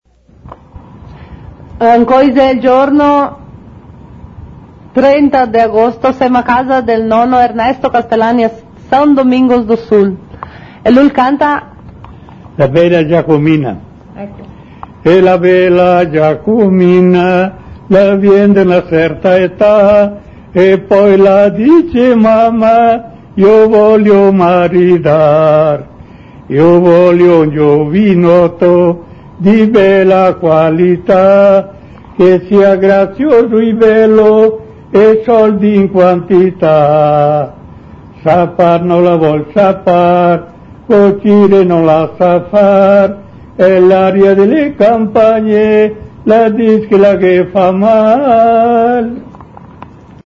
La vecia Giacomina Storieta